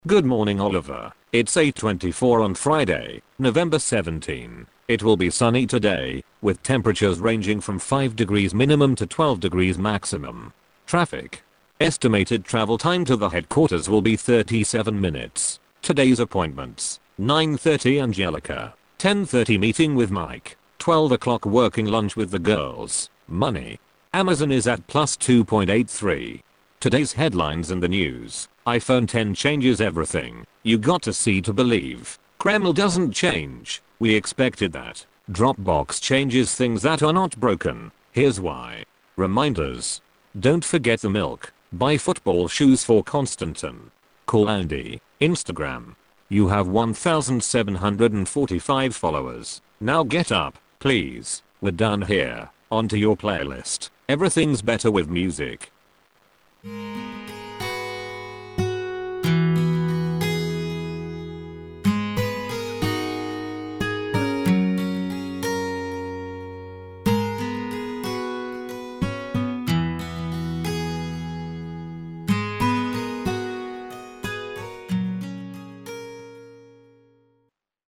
Talking Alarm Clock
TalkClok uses your device’s built in voices.
iOS built in